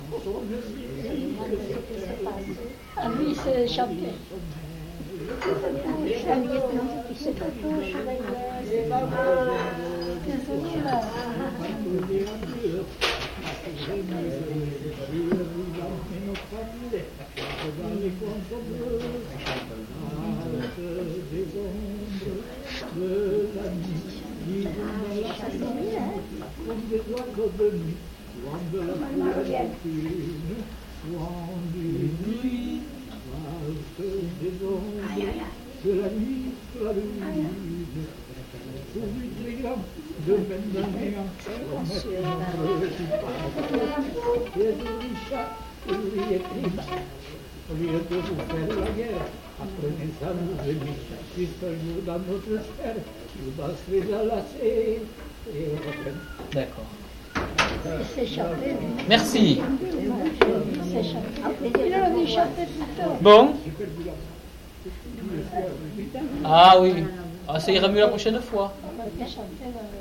Aire culturelle : Couserans
Lieu : Castillon-en-Couserans
Genre : chant
Effectif : 1
Type de voix : voix d'homme
Production du son : chanté
Notes consultables : Très peu audible.